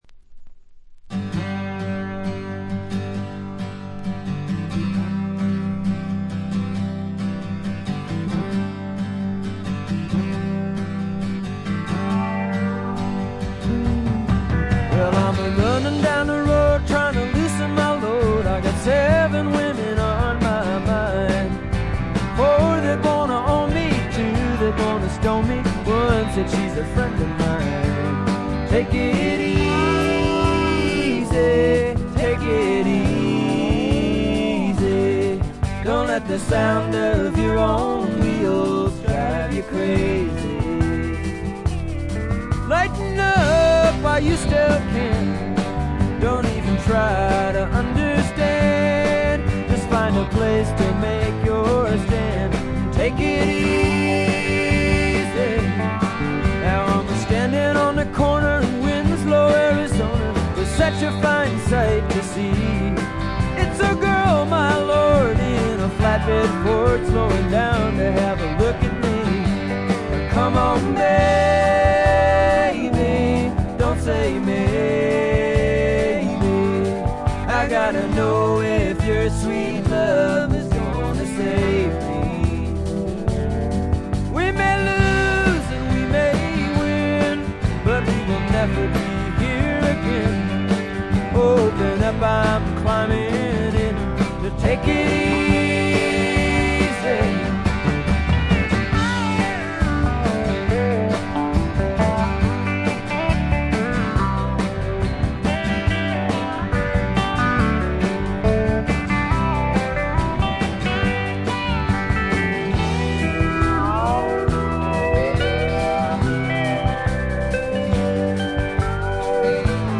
シンガー・ソングライター物としては完璧な1枚ではないでしょうか。